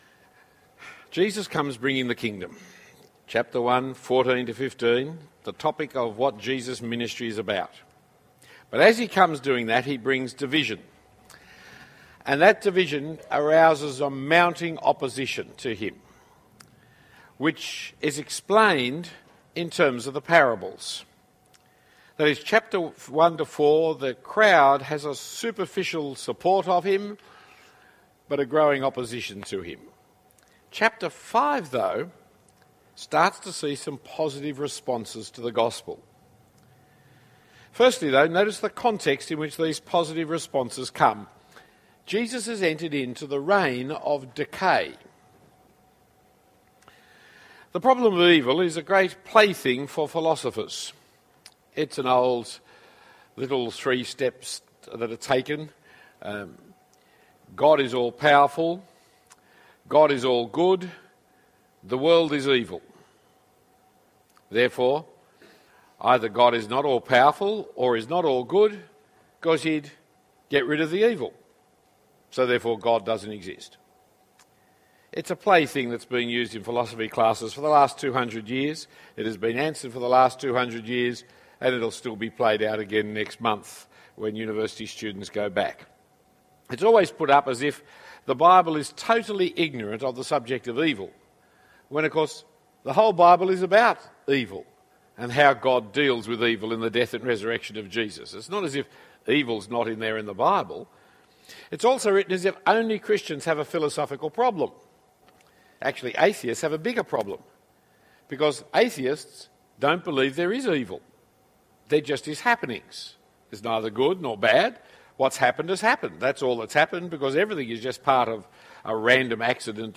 Talk 5 of 16 in the series Mark 2008 given at St Andrews Cathedral.